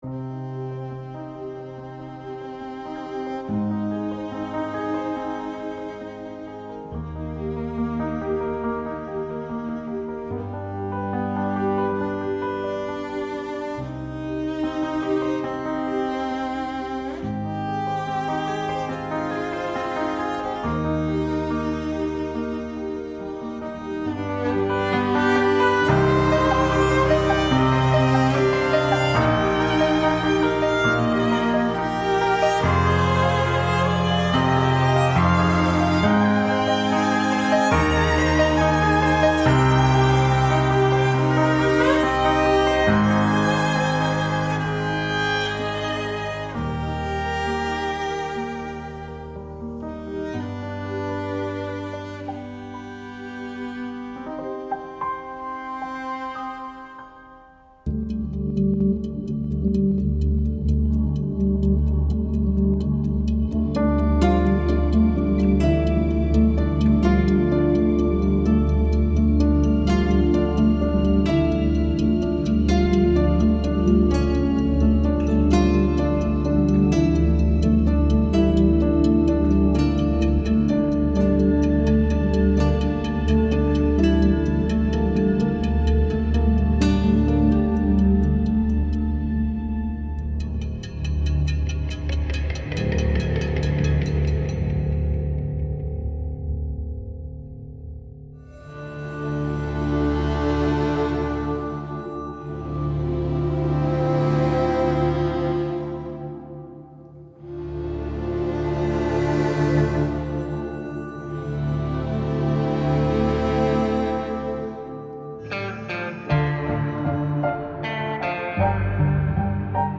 TV Drama-Film-Documentary Excerpts (4’38”)